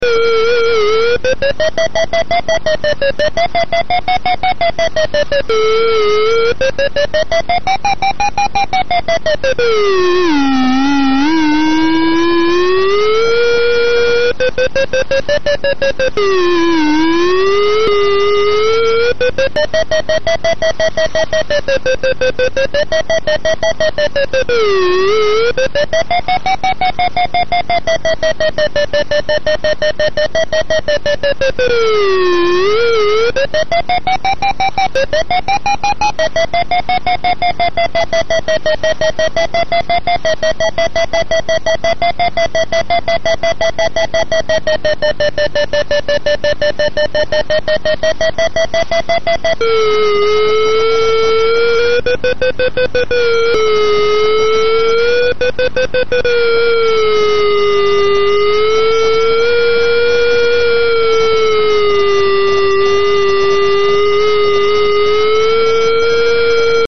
Glider vario sound
Taken from a YouTube video of a vario test ... this is the sound that sets glider pilots' pulses racing